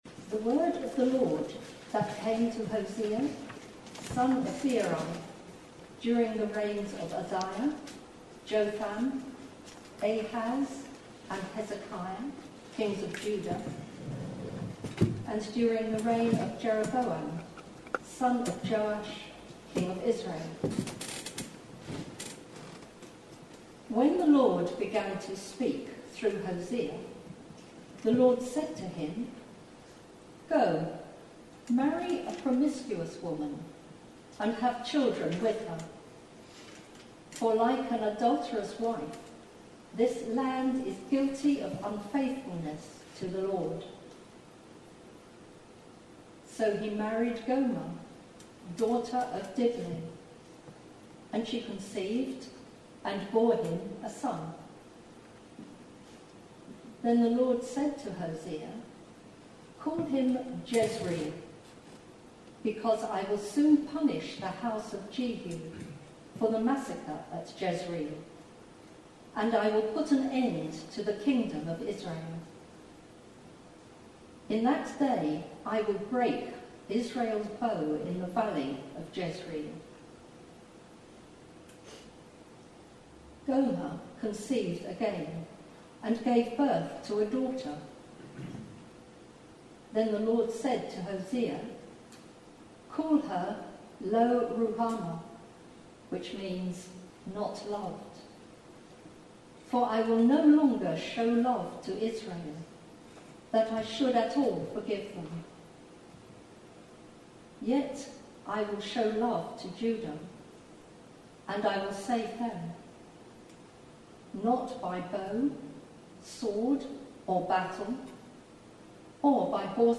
(apologies for the poor sound quality – this is not our normal standard!)